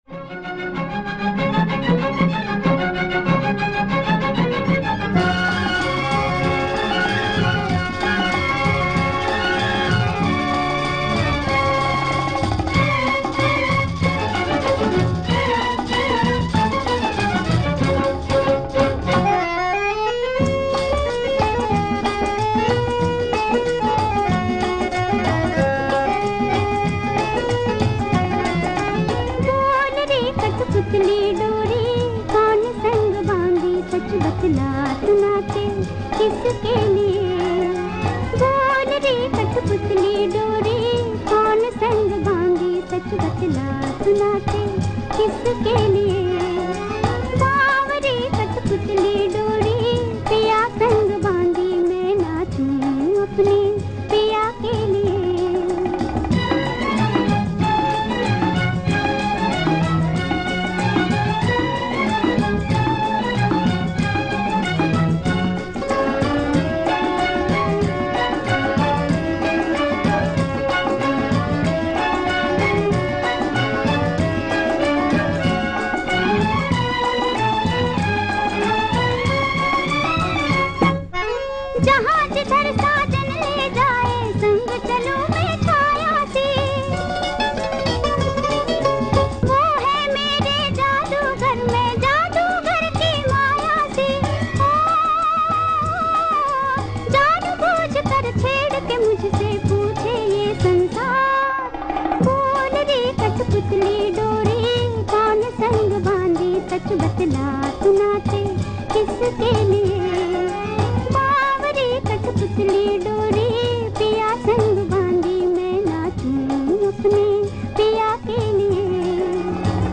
• Speaker: Singer